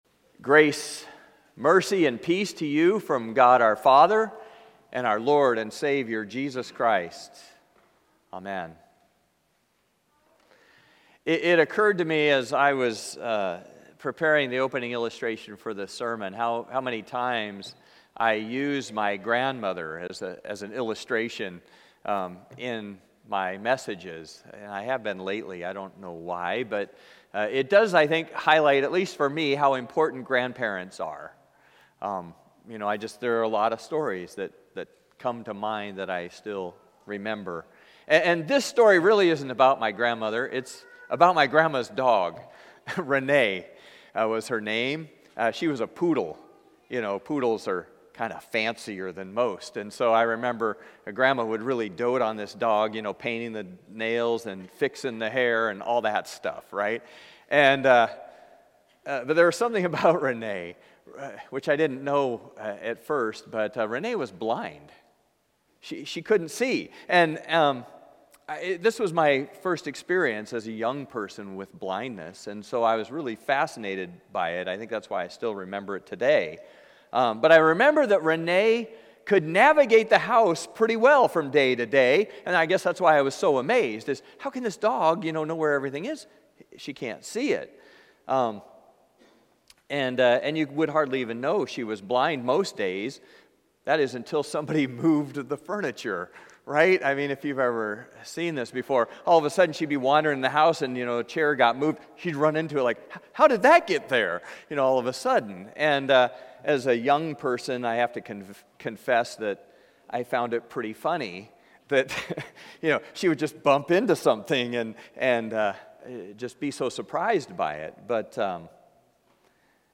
Passage: John 9:1-7, 13-17, 24-41 Service Type: Traditional and Blended « Lent Midweek 3